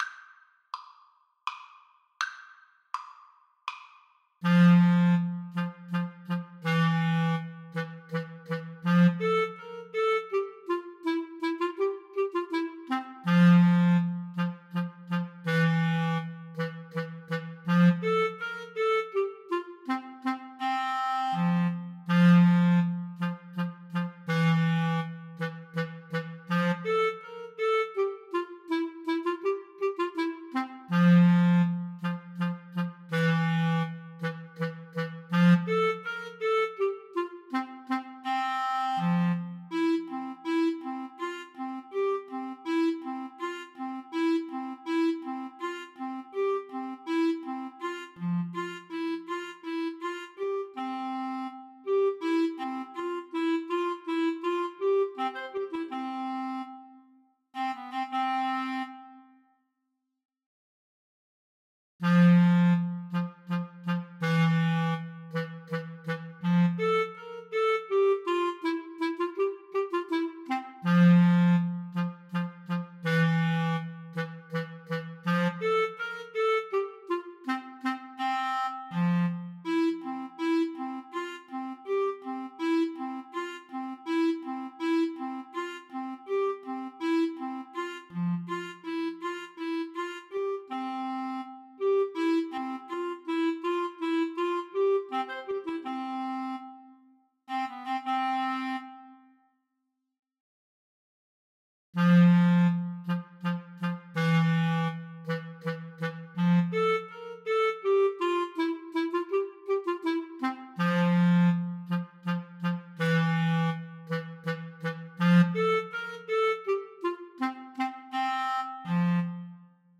Clarinet 1Clarinet 2
3/4 (View more 3/4 Music)
Classical (View more Classical Clarinet Duet Music)